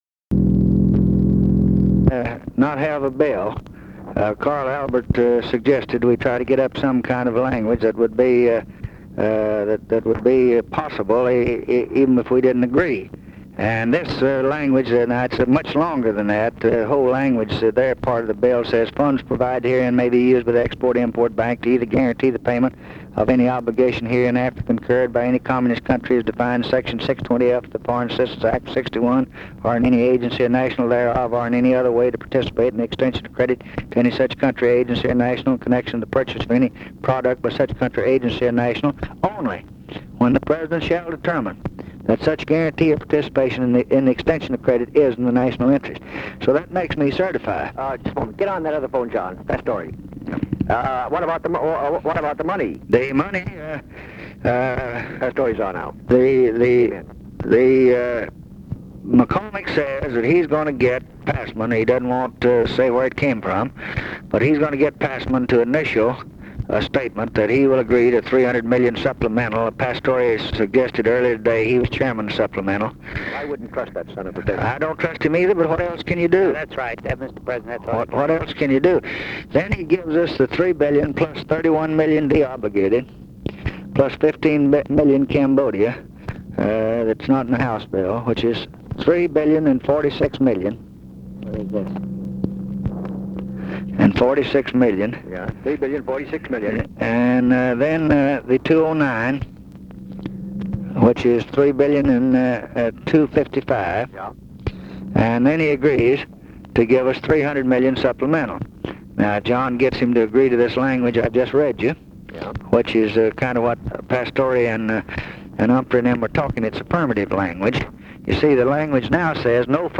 LBJ READS LANGUAGE FOR WHEAT AMENDMENT TO FOREIGN AID APPROPRIATIONS BILL; OTTO PASSMAN; MILITARY ASSISTANCE PROGRAM
Conversation with MIKE MANSFIELD, December 21, 1963
Secret White House Tapes